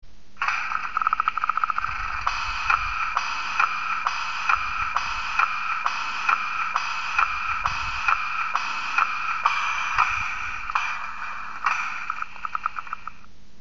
BR 18.4 mit ESU Loksound mfx Sound 2:
Sound 2 ist glaub ich die Kolbenspeisepumpe